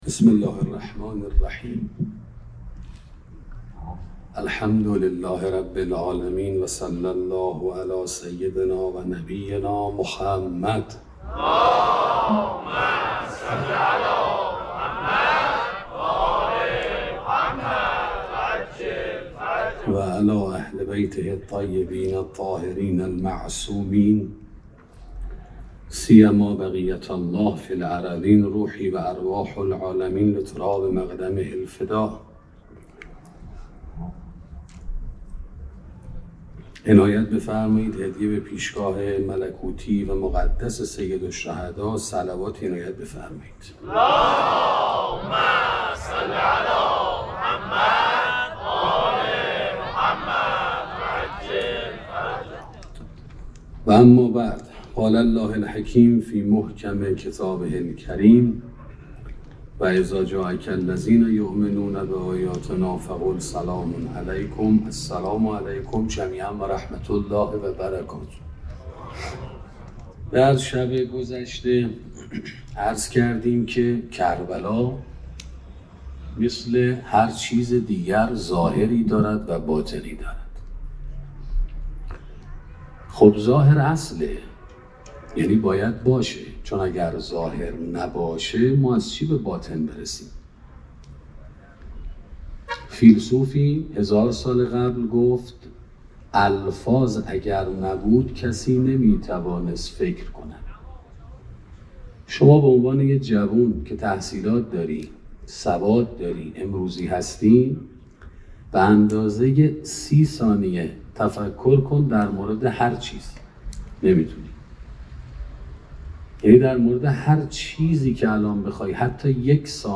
سخنرانی شرح زیارت عاشورا 2